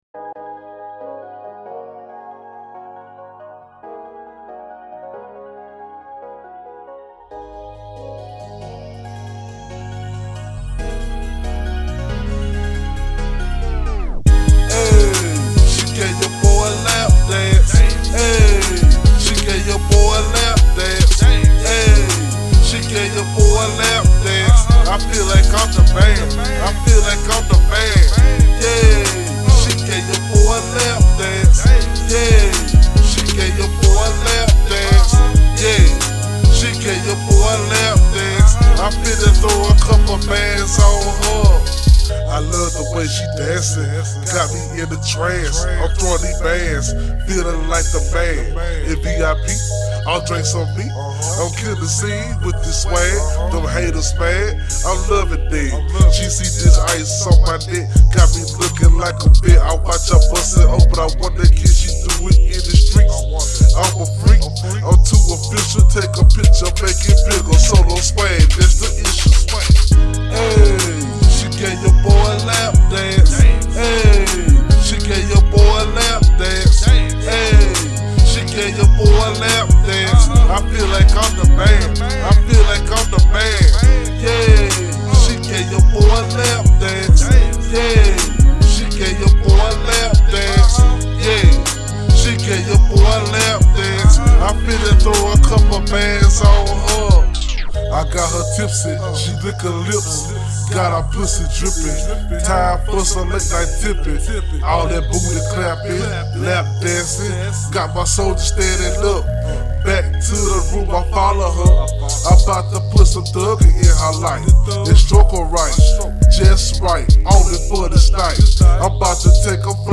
Description : THE NEW STRIP CLUB ANTHEM